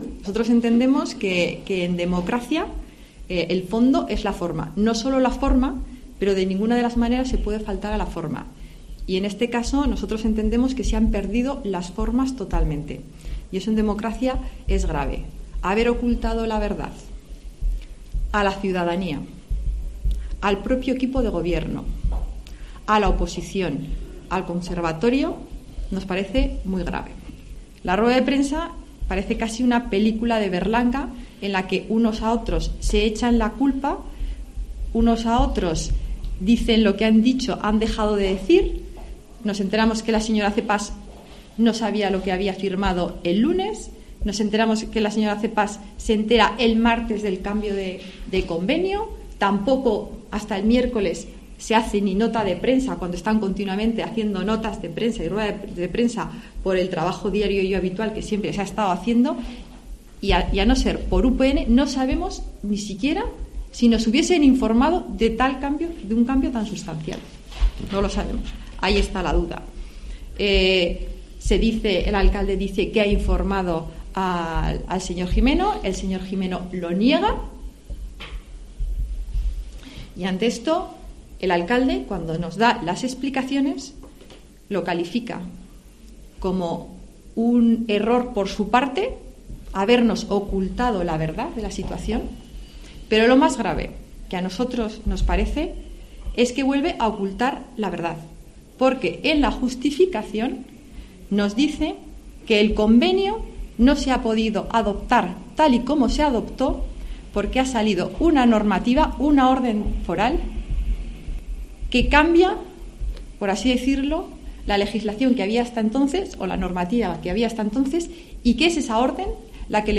Rueda Prensa PP Tudela (Conservatorio)